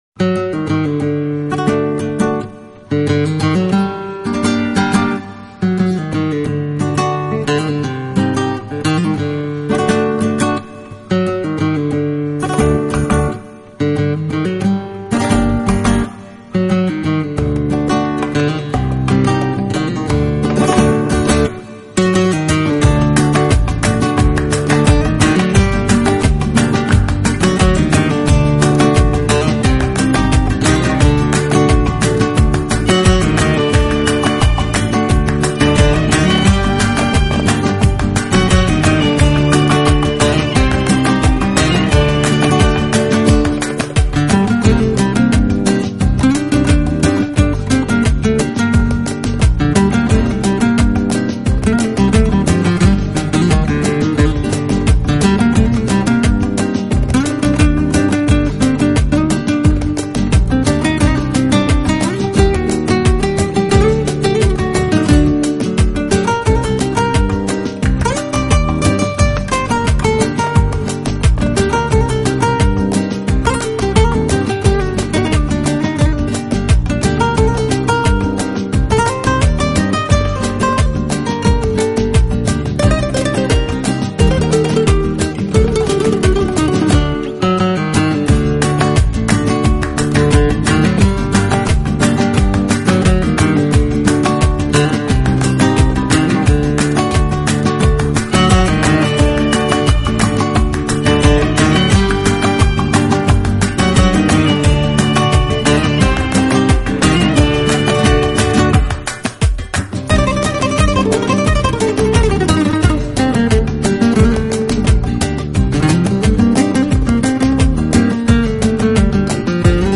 音乐类型：World Fusion, Neo Flamenco